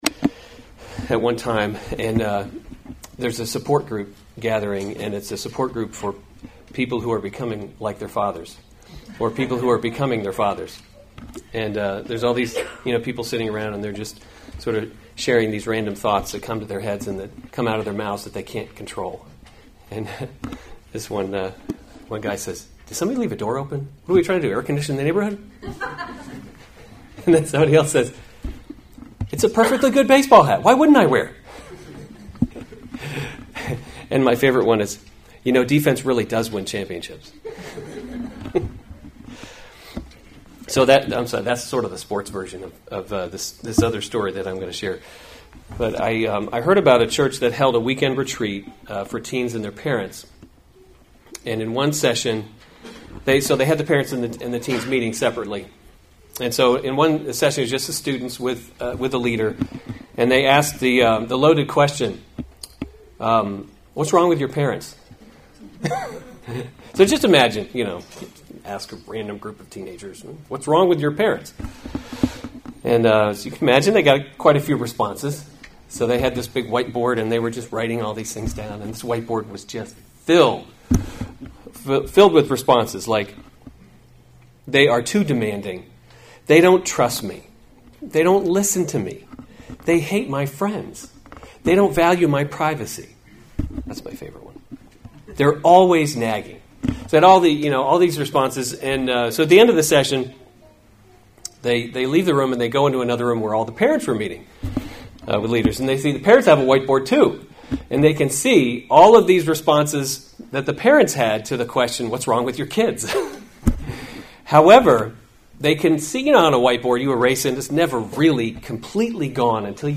March 23, 2019 1 Kings – Leadership in a Broken World series Weekly Sunday Service Save/Download this sermon 1 Kings 14:21 – 15:24 Other sermons from 1 Kings Rehoboam Reigns […]